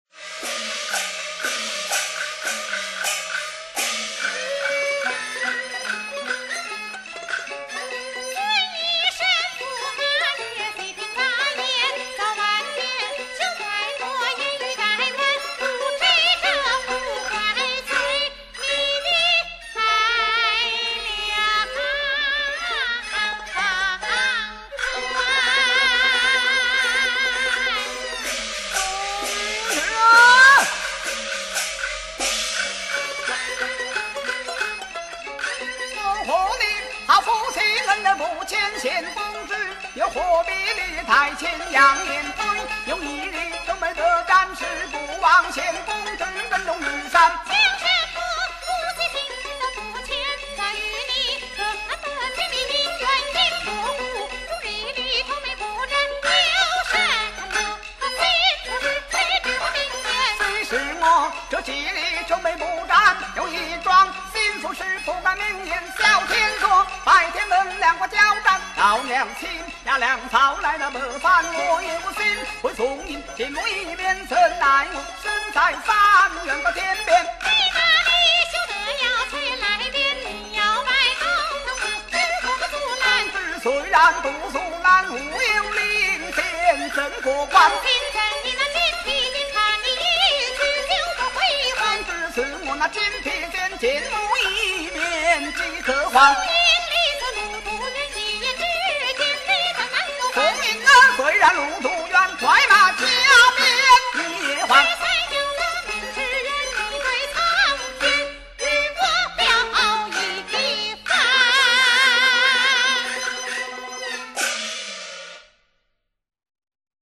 [对唱]